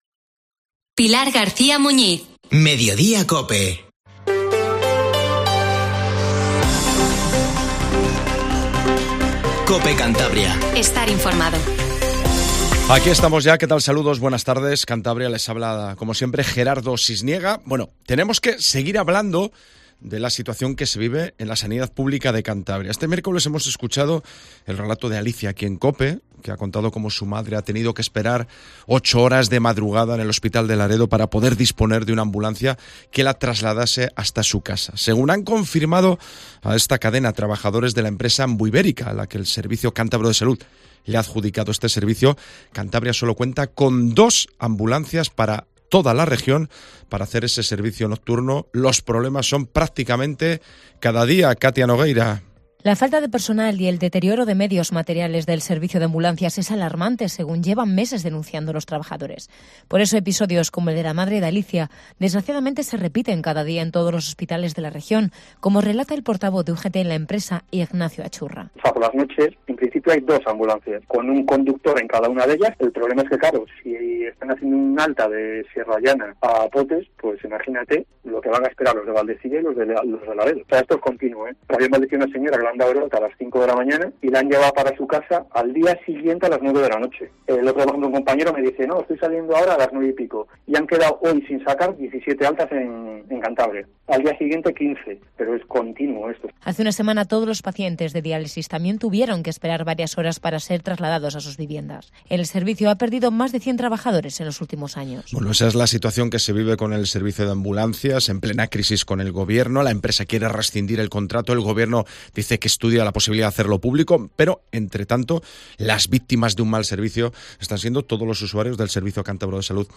Informativo Mediodía COPE CANTABRIA